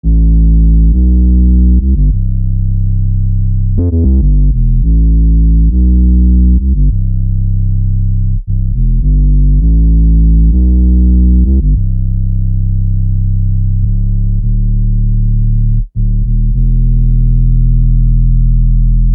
Bass 10.wav